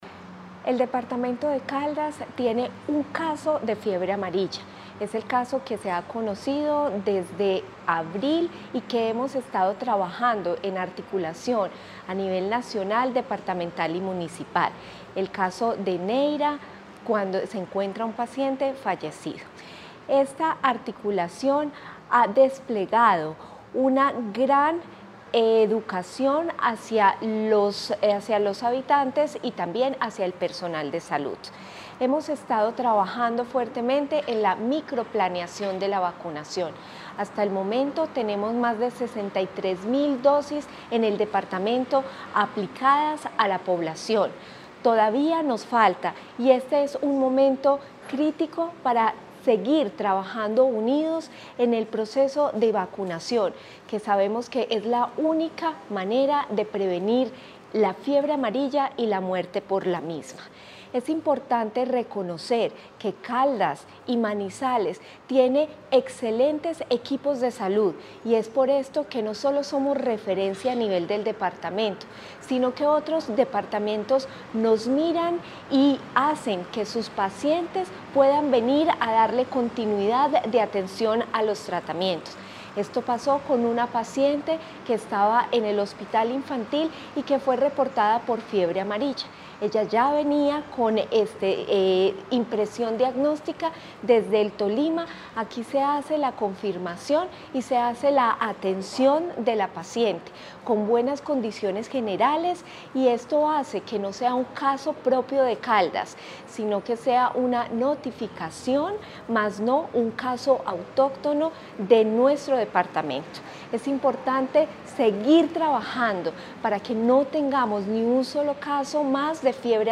Natalia Castaño Díaz, directora de la DTSC
AUDIO-NATALIA-CASTANO-DIAZ-DIRECTORA-DTSC-TEMA-FIEBRE-AMARILLA.mp3